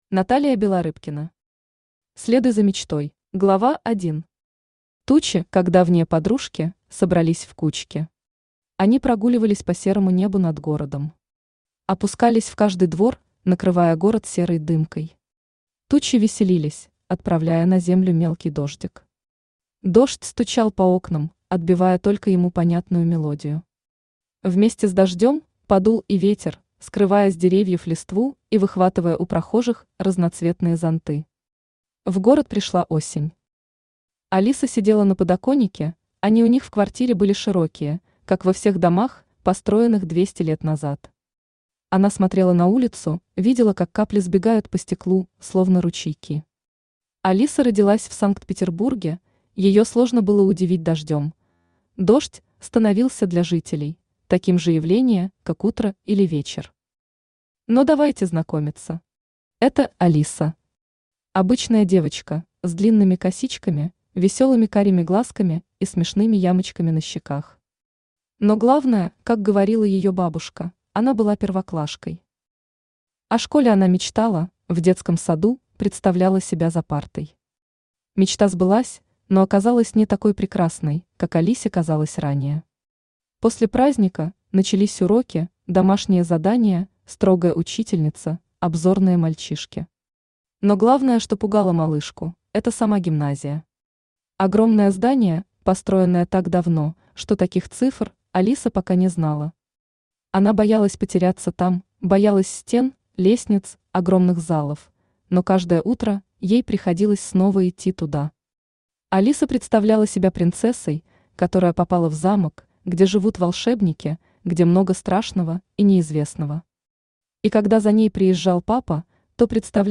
Аудиокнига Следуй за мечтой | Библиотека аудиокниг
Aудиокнига Следуй за мечтой Автор Наталия Вячеславовна Белорыбкина Читает аудиокнигу Авточтец ЛитРес.